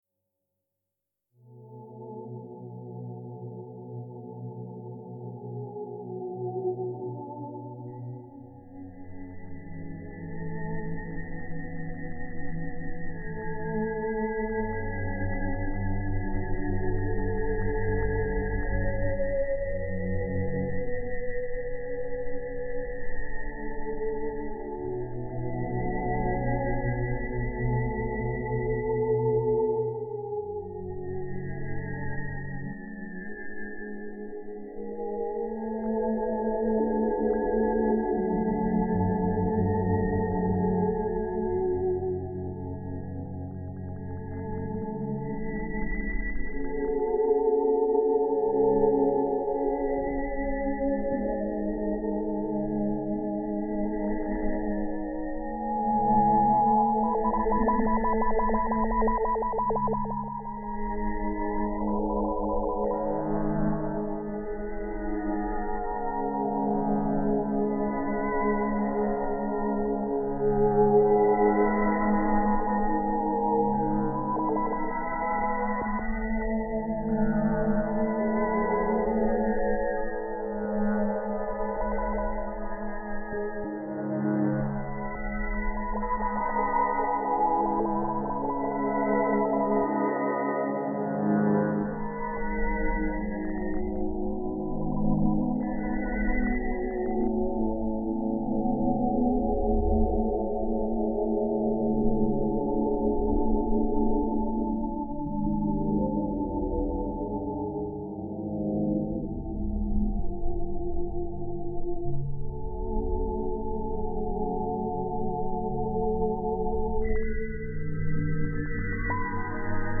Singing around synthesis